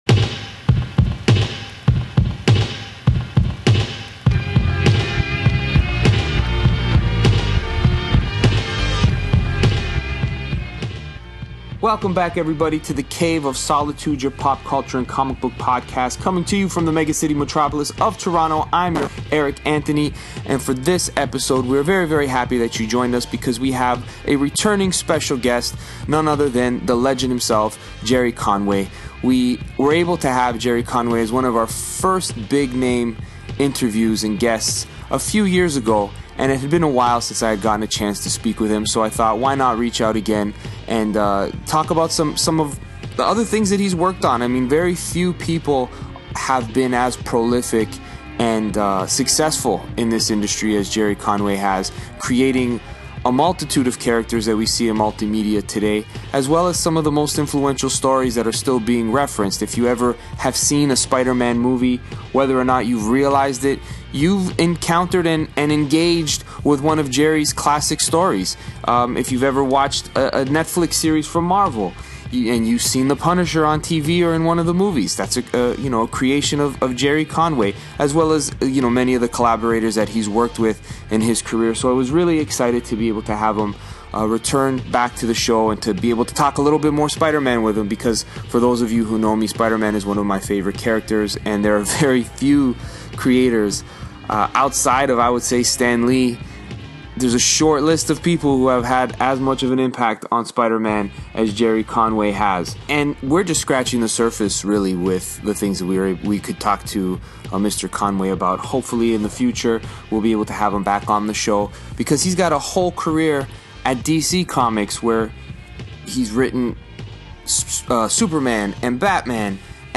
Comic book legend, Gerry Conway returns to the Cave to chat it up